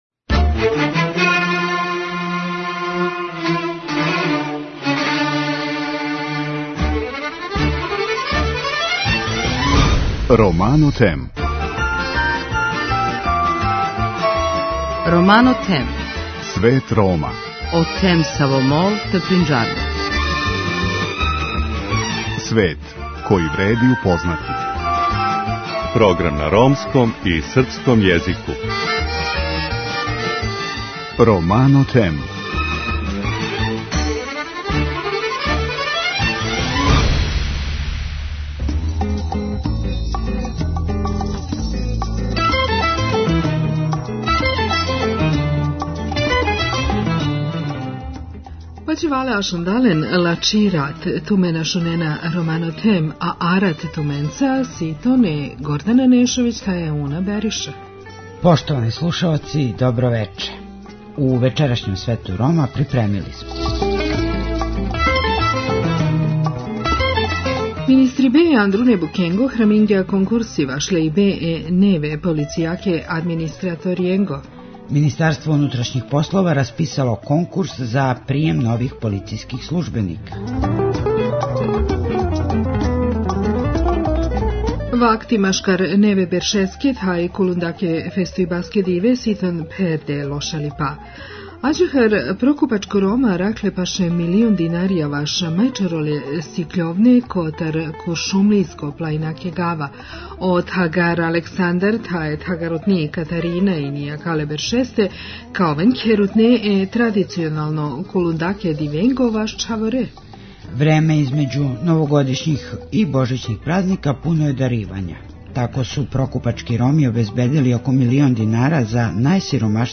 Гост Света Рома је Саша Јанковић, заштитник грађана Републике Србије који говори о томе шта је, по њему, учињено на пољу поштовања људских права Рома.